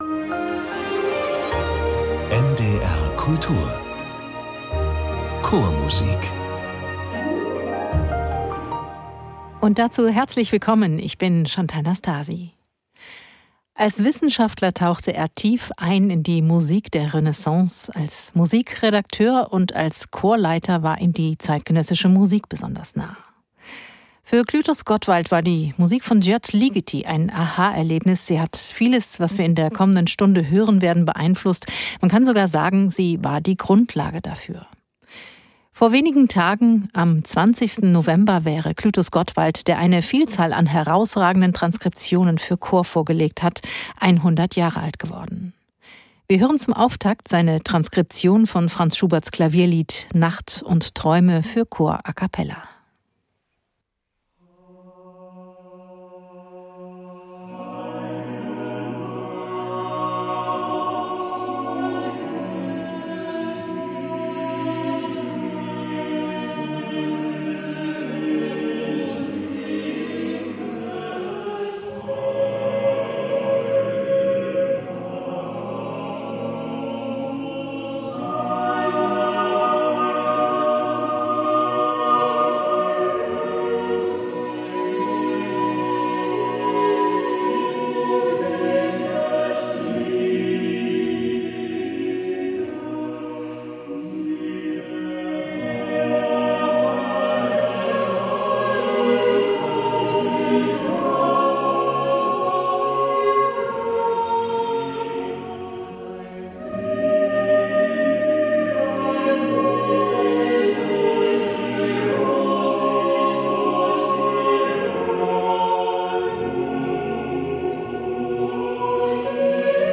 Klavierlieder der Romantik: Der Kammerchor Stuttgart hat Gottwalds A-cappella-Transkriptionen neu aufgenommen.